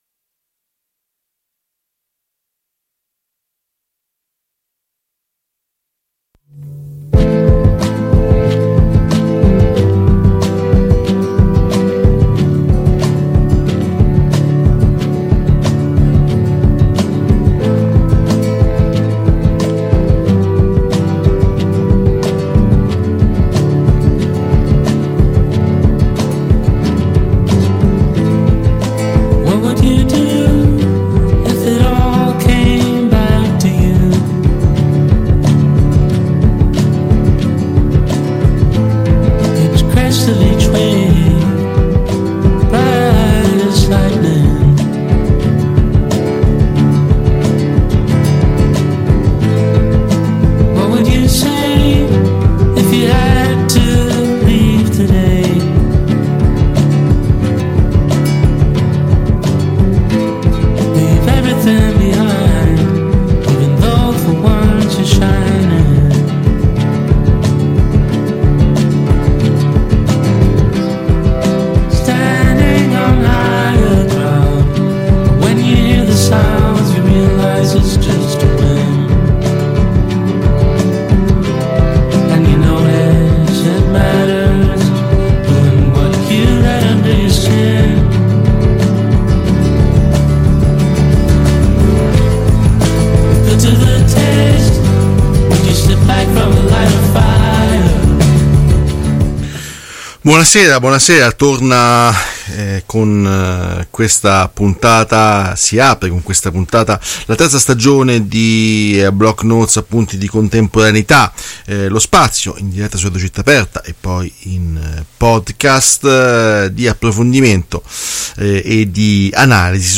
trasmesso in diretta ogni giovedì alle 22